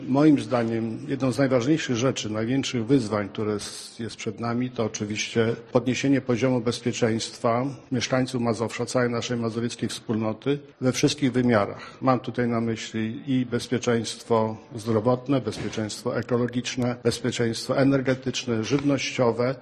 Nasz cel to zapewnienie bezpieczeństwa mieszkańcom- mówi Adam Struzik, marszałek województwa mazowieckiego: